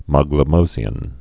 (mäglə-mōzē-ən)